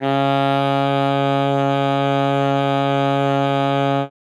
42c-sax01-c#3.wav